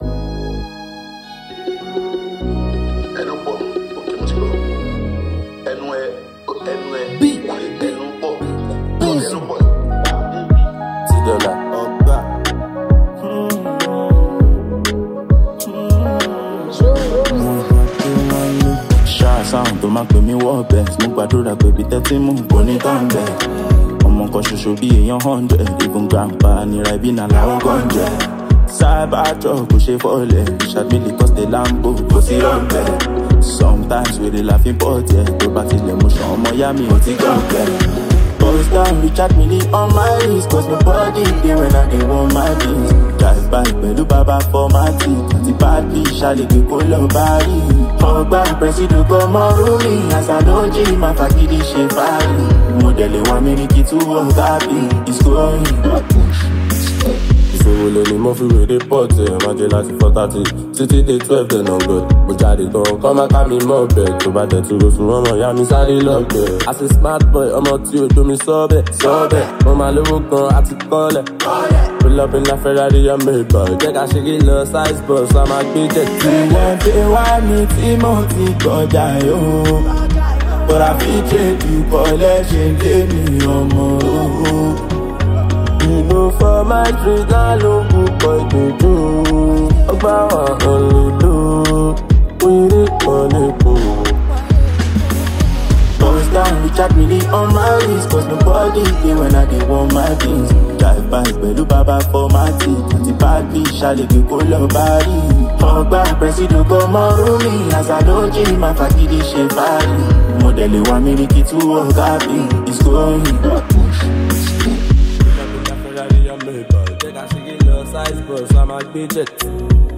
Nigeria talented Afrobeats music singer and songwriter